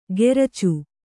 ♪ geracu